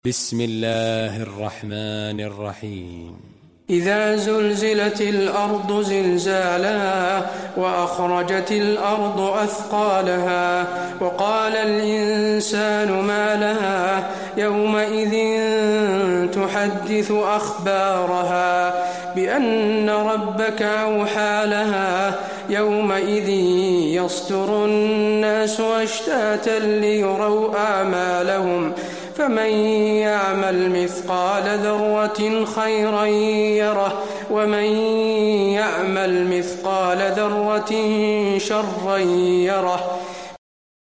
المكان: المسجد النبوي الزلزلة